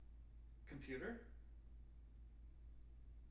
wake-word
tng-computer-374.wav